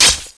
assets/common/sounds/menu/enter.wav at b5672faeaa3ddb259391cd01b51d274b252b6a5e
enter.wav